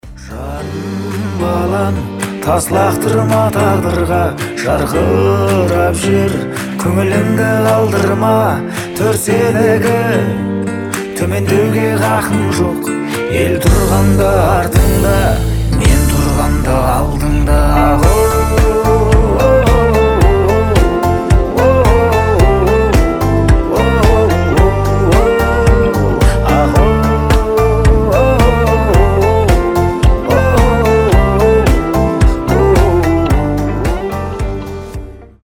Душевные
Поп